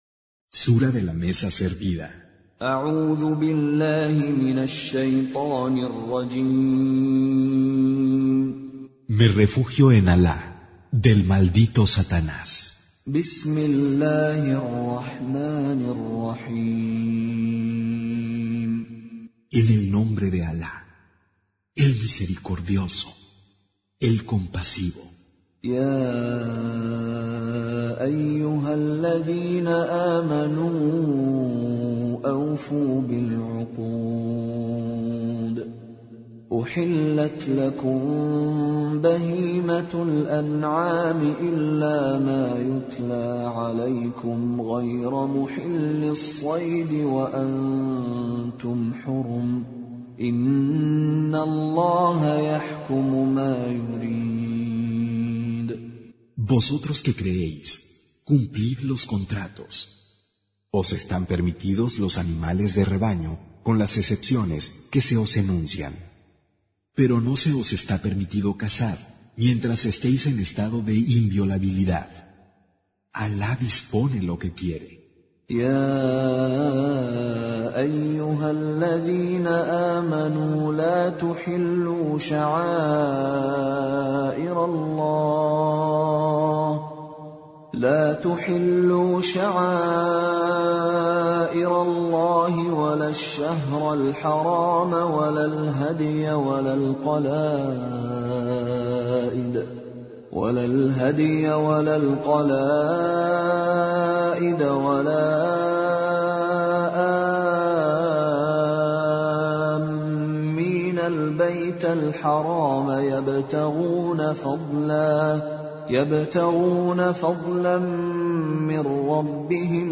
Traducción al español del Sagrado Corán - Con Reciter Mishary Alafasi